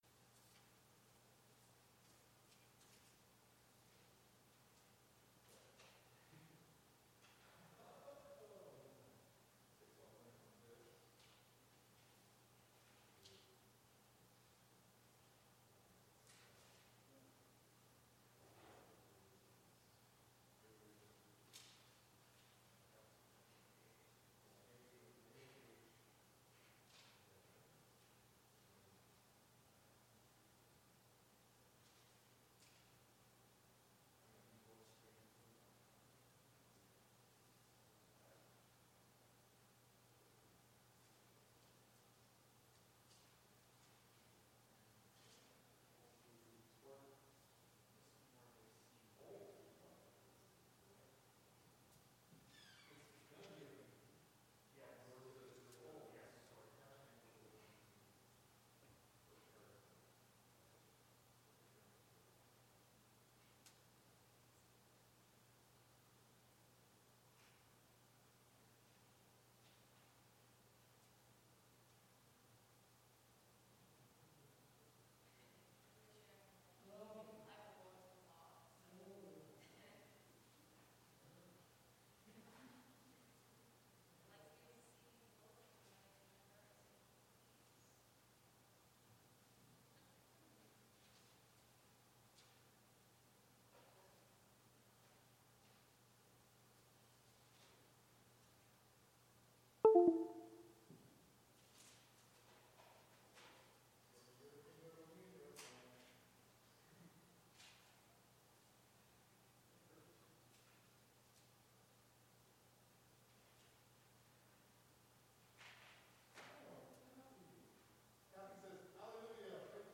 Worship
April 4th, 2021 Service Audio